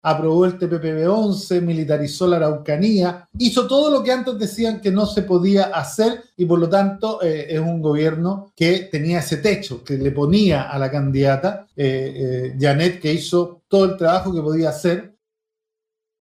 En su programa “Sin Maquillaje”, el militante del Partido Comunista responsabilizó directamente al gobierno del Presidente Gabriel Boric por el desenlace electoral.